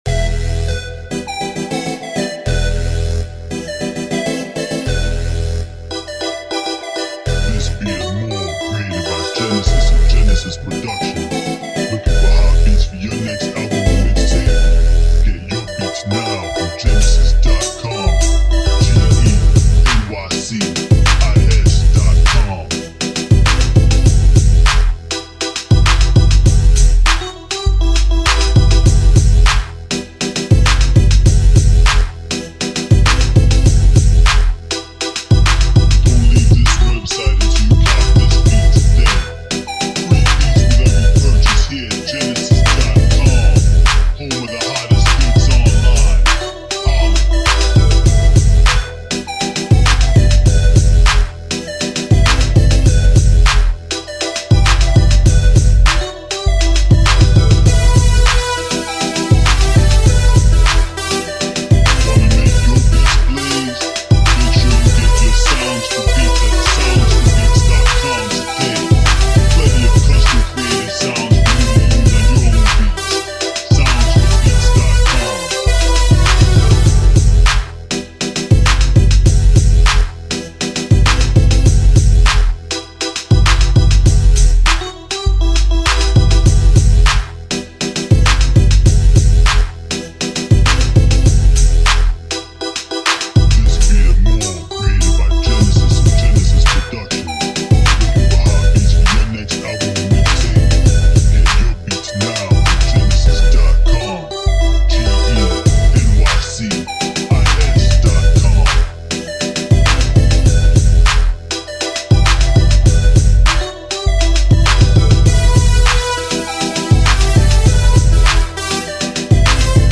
Fast Paced Club Beat